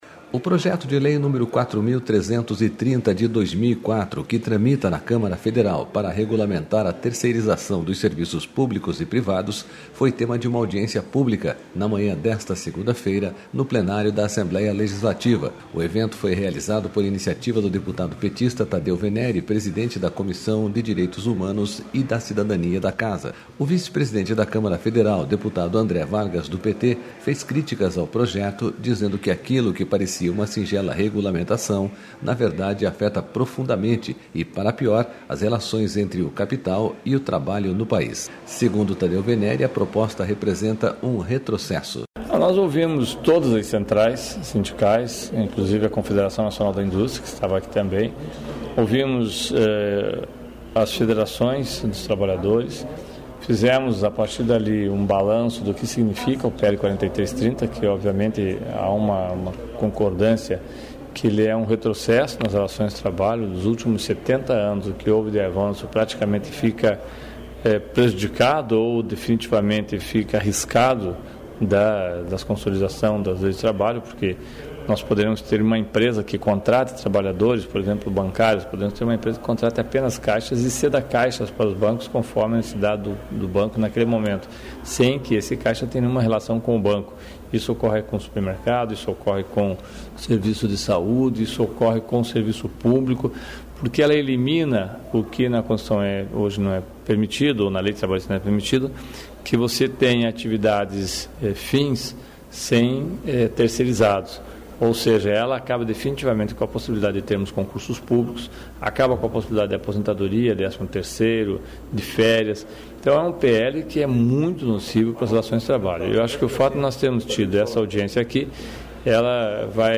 Audiência pública mostra preocupação dos trabalhadores com projeto das terceirizações